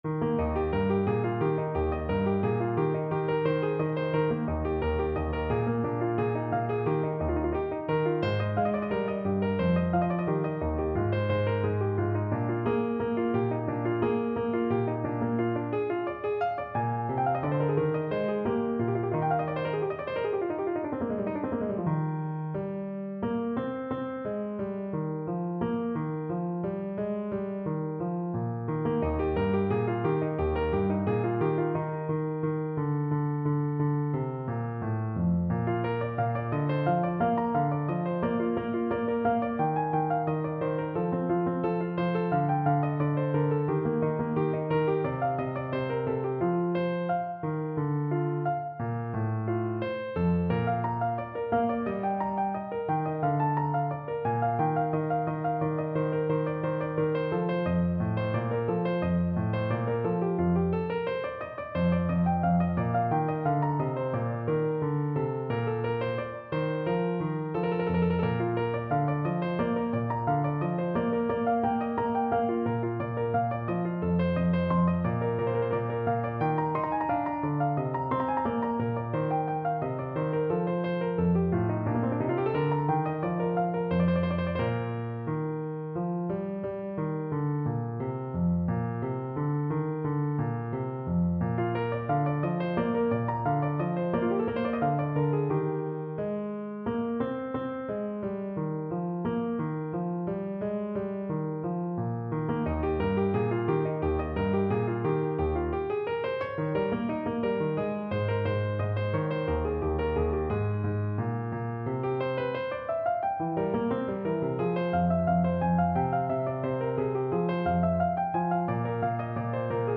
= 88 Allegro Moderato (View more music marked Allegro)
4/4 (View more 4/4 Music)
Flute  (View more Advanced Flute Music)
Classical (View more Classical Flute Music)